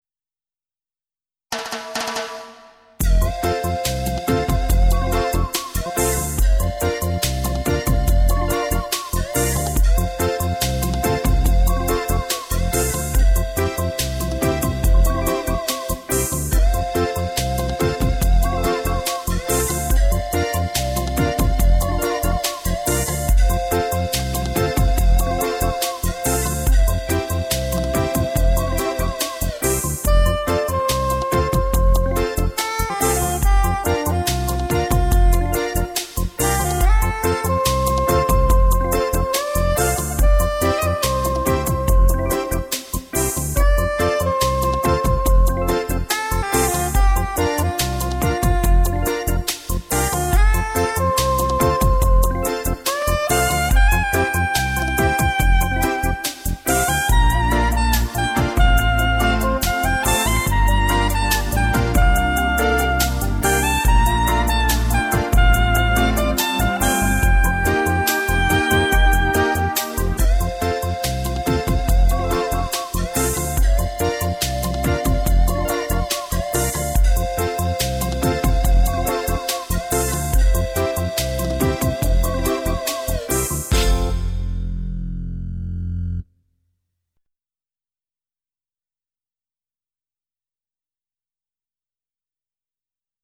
Reggae,Beach(1457K)